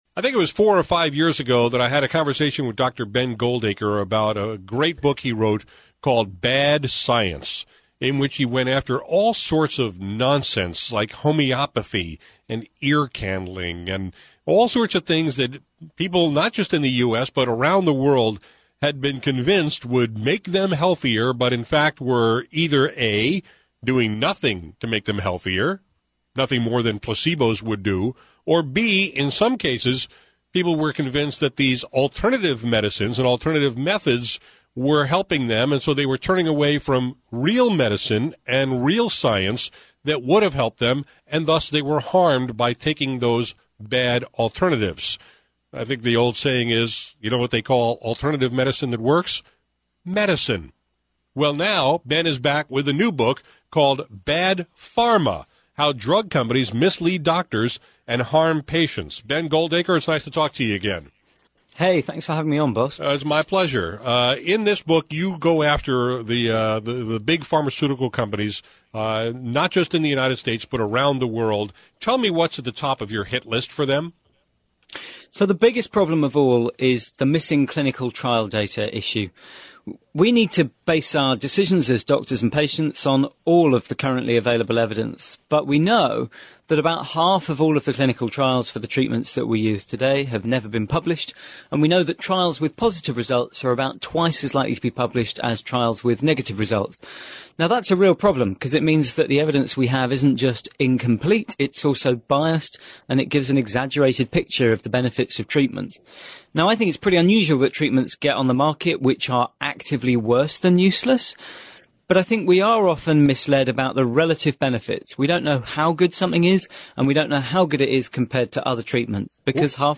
He joined me today on KTRS to talk about two troubling aspects of the industry — how Big Pharma withholds negative data from clinical trials about drugs that reach the market, and how regulators aren’t doing the job necessary to keep those dangerous drugs from reaching consumers.